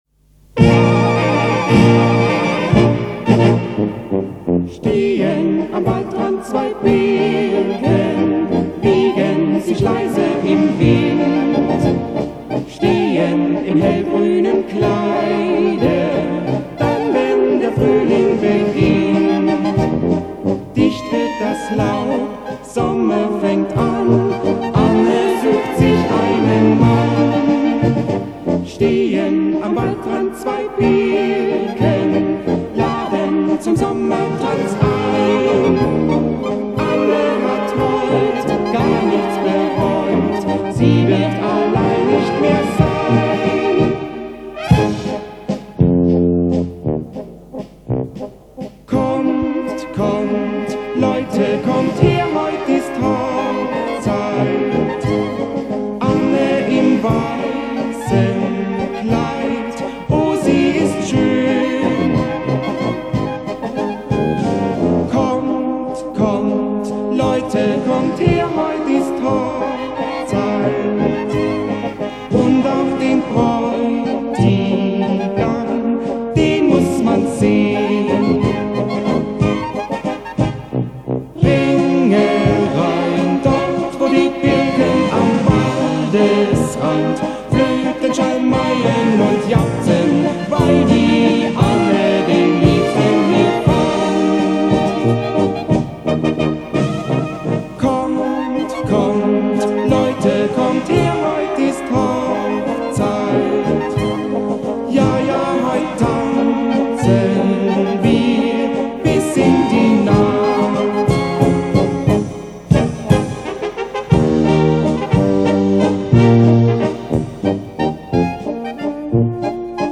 Walzer mit Gesang